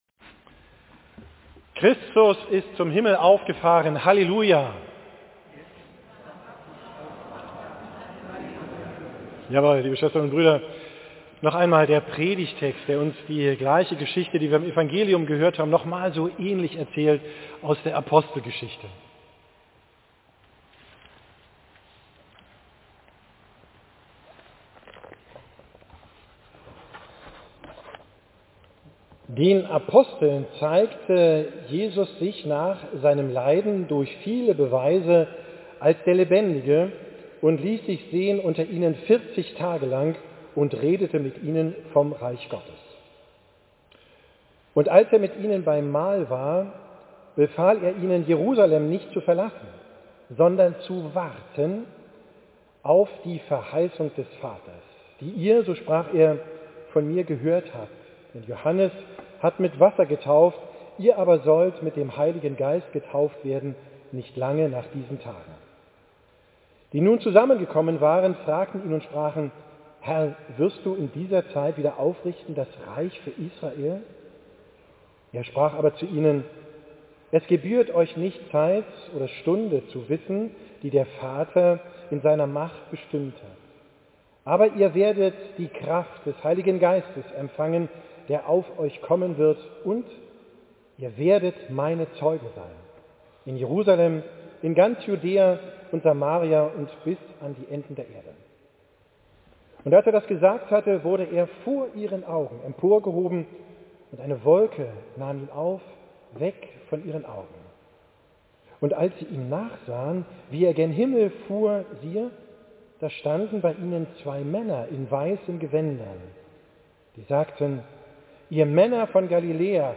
Predigt vom Fest Christi Himmelfahrt, 9.